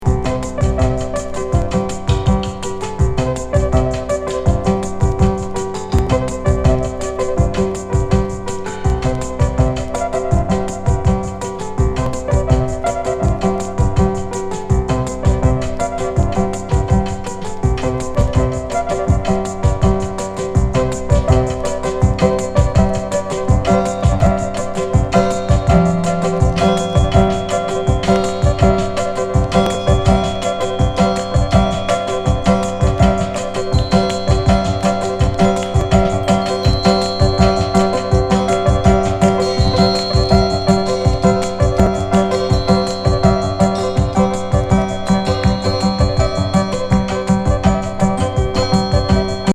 73年作!エクスペリメンタル～エレポップへの過渡期にあった、極上エレクトロニクス!